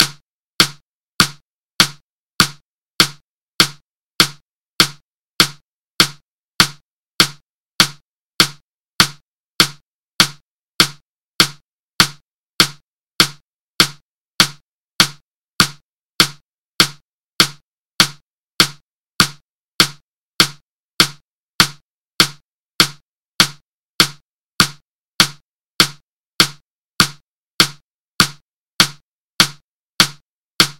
Metronome at 100bpm
100bpm.mp3